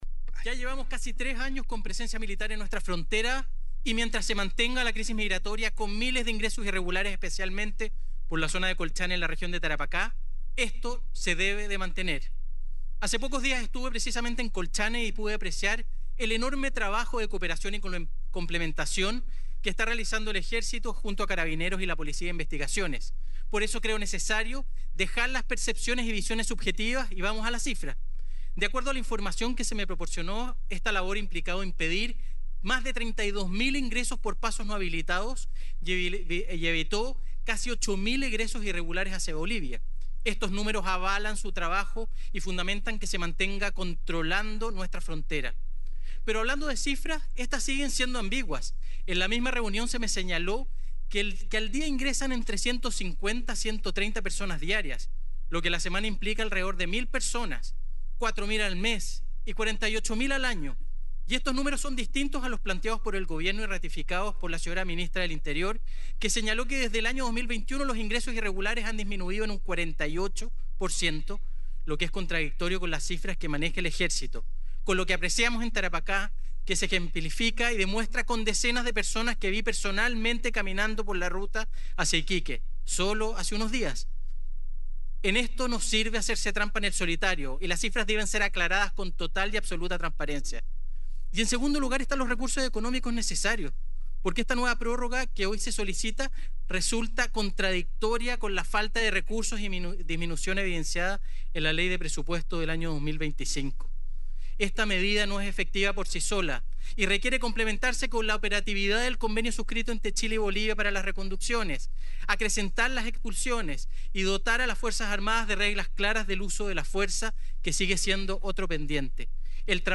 Discusión en Sala
En la sesión de esta jornada, el diputado de la Bancada Republicana, Renzo Trisotti (Ex UDI), manifestó su apoyo a la prórroga de la medida, enfatizando la importancia de fortalecer los recursos y las reglas de uso de la fuerza para las Fuerzas Armadas.